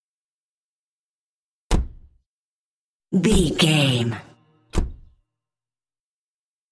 Car door close
Sound Effects